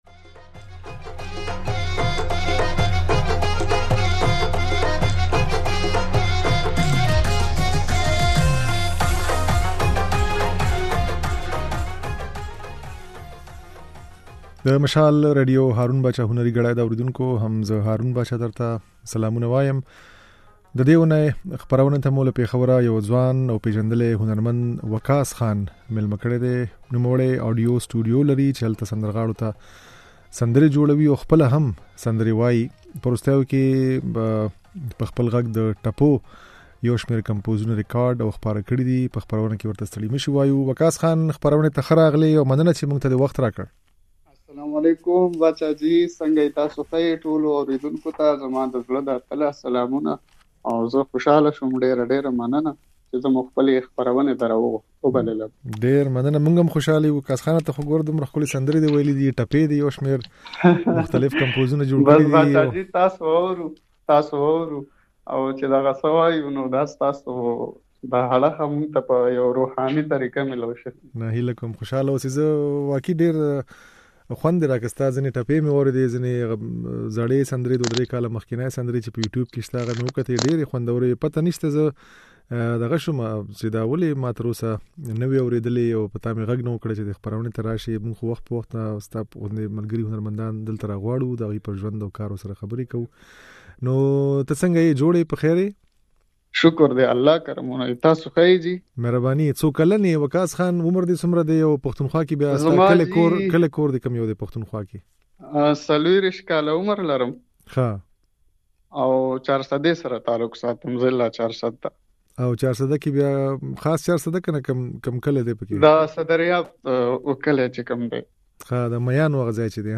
ځينې سندرې يې په خپرونه کې اورېدای شئ